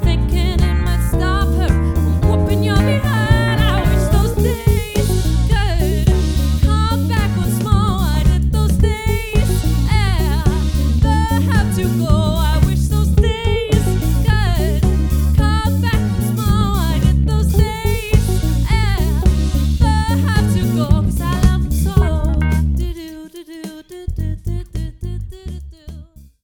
Vokal
Guitar
Keys
Trommer
• Coverband